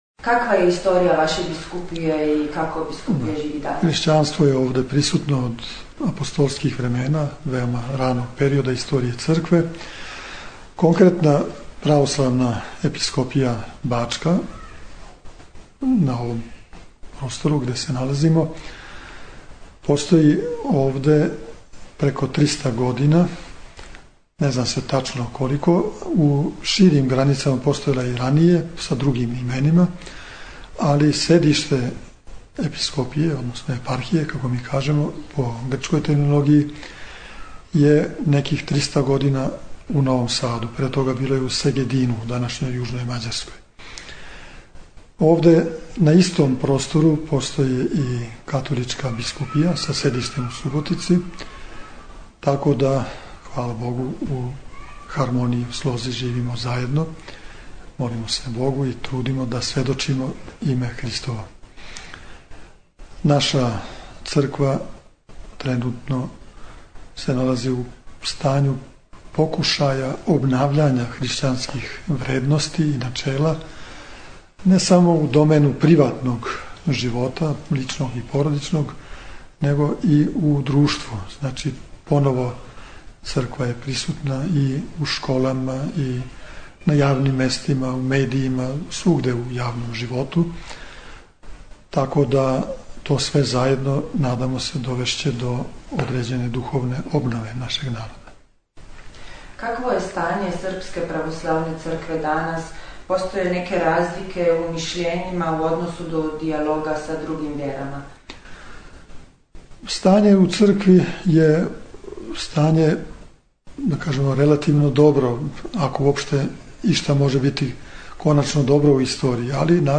Интервју Епископа бачког др Иринеја Телевизији Словенија
Наш Епископ господин др Иринеј сусрео се са ходочасницима и, том приликом, дао кратак интервју за Верску редакцију Телевизије Словенија.
Звучни запис интервјуа
intervju_vl_Irinej.mp3